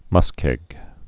(mŭskĕg)